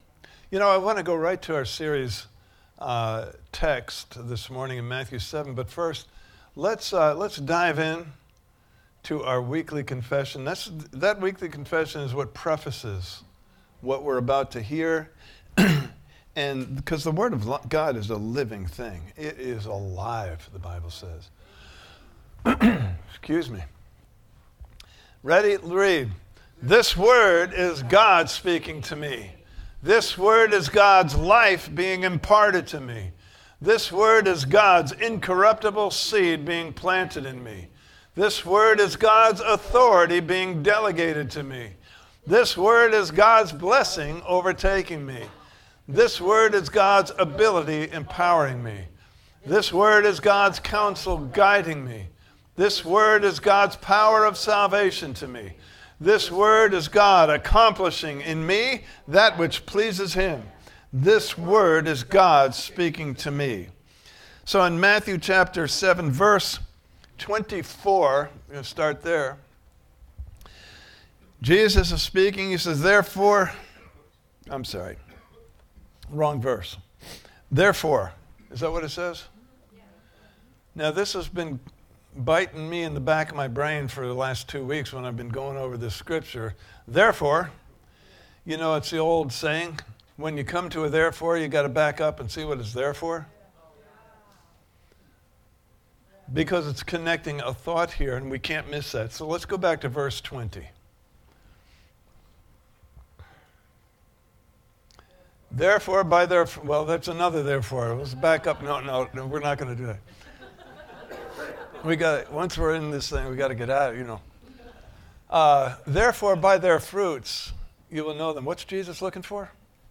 Standing on a Firm Foundation Service Type: Sunday Morning Service « Part 2